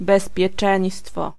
Ääntäminen
US
IPA : /ˈseɪftɪ/